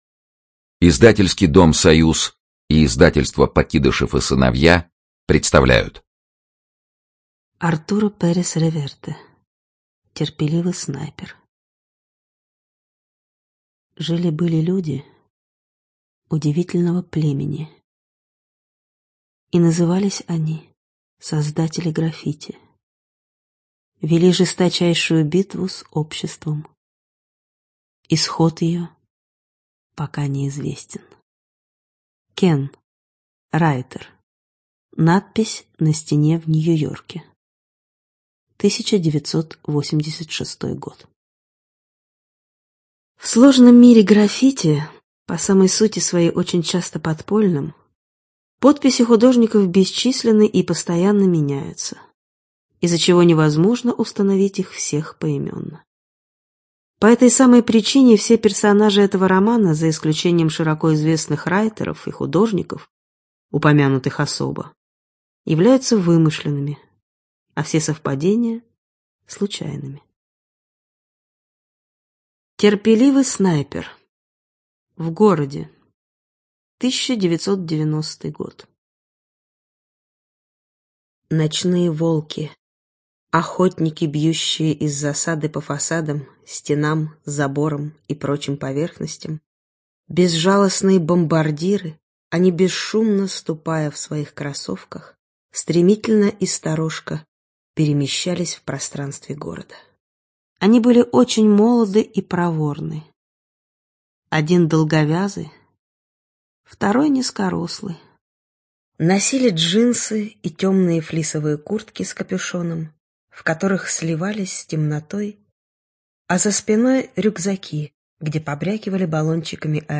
Студия звукозаписиСоюз
Представляем вашему вниманию аудиоверсию детектива «Терпеливый снайпер» от автора нашумевших бестселлеров «Клуб Дюма или тень Ришелье», «Танго старой гвардии» и «Капитан Алатристе». Вас ждет удивительная встреча с миром граффити, стрит-арта и людьми, для которых это искусство стало образом жизни.